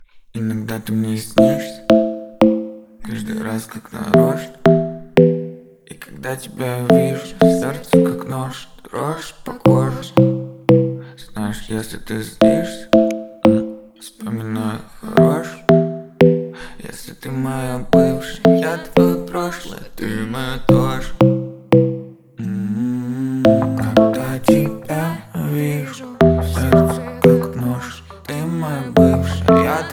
Жанр: Альтернатива / Русские